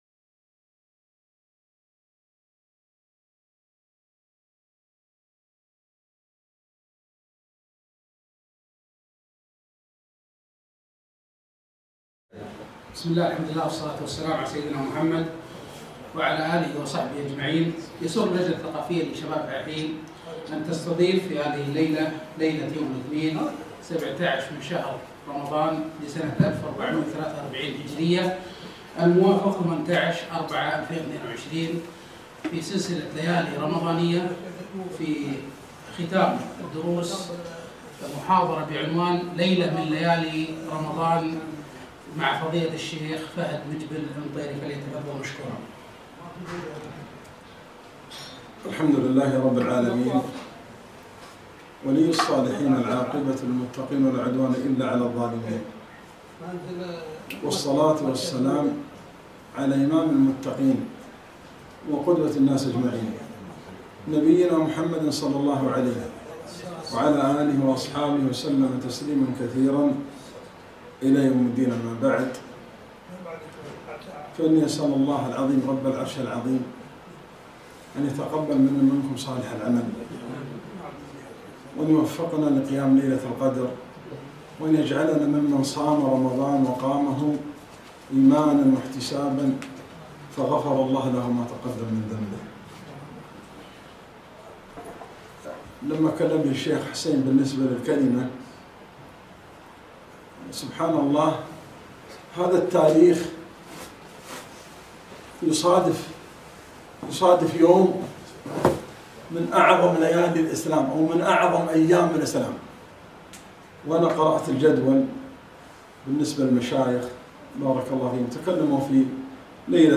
محاضرة - من ليالي رمضان